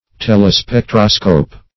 Search Result for " telespectroscope" : The Collaborative International Dictionary of English v.0.48: Telespectroscope \Tel`e*spec"tro*scope\, n. [Gr.
telespectroscope.mp3